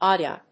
/ˈɑdiʌ(米国英語), ˈɑ:di:ʌ(英国英語)/
フリガナアディーア